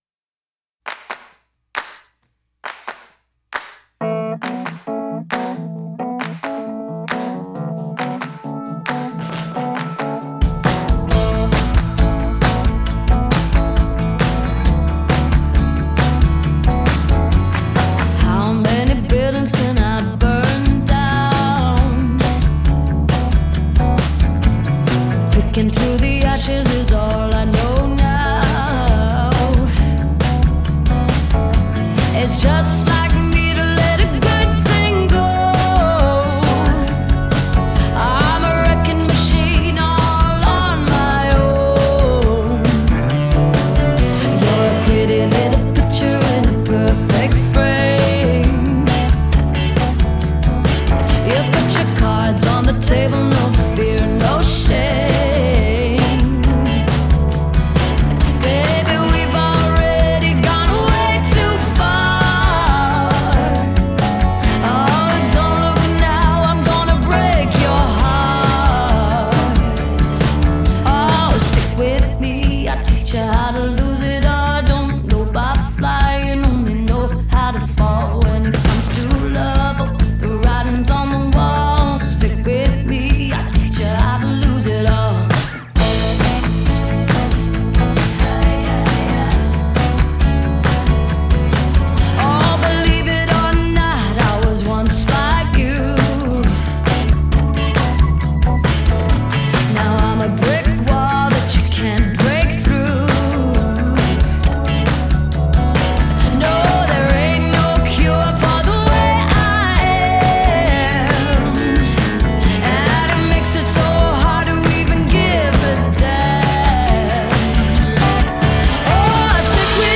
Genre: pop, female vocalists, singer-songwriter